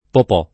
popò [ pop 0+ ] (pop. tosc. poppò ) s. f.